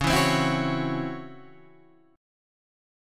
C# Minor Major 7th Double Flat 5th